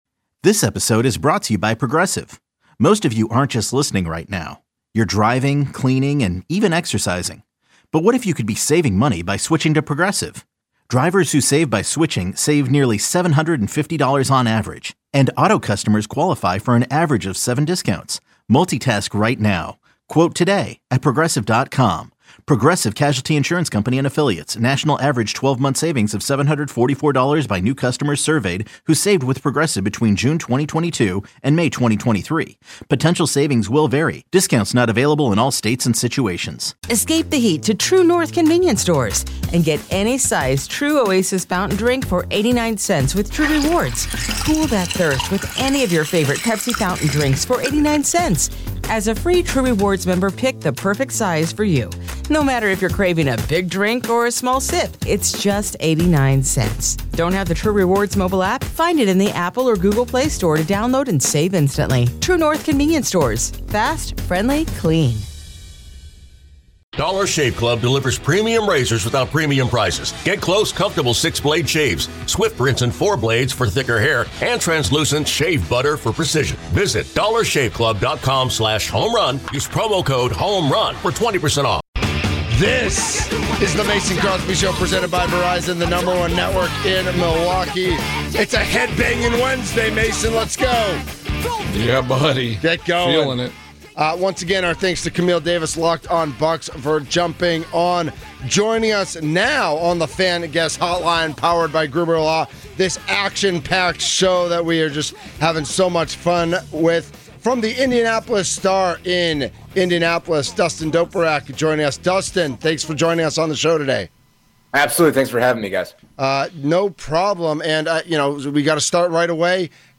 07-02-25 The Mason Crosby Show Interviews